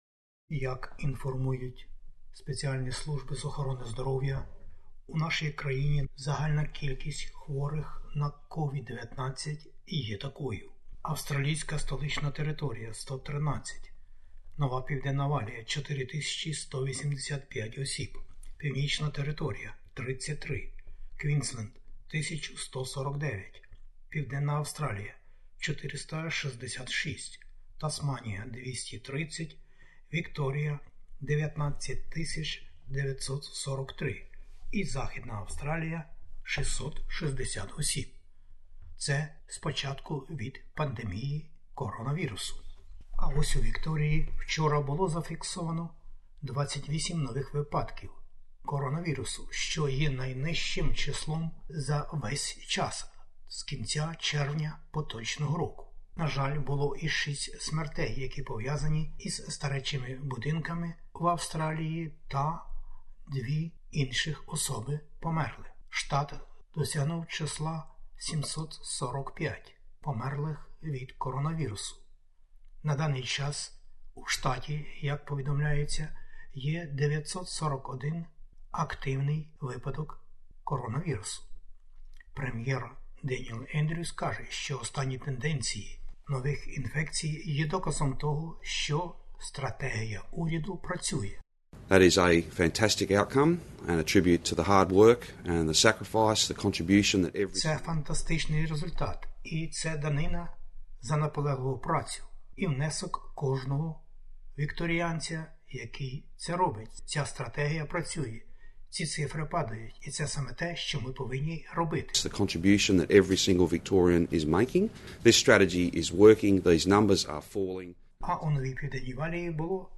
SBS НОВИНИ УКРАЇНСЬКОЮ